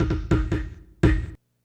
drums04.wav